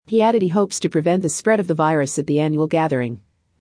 このままの速度でお聞きください。
【ノーマル・スピード】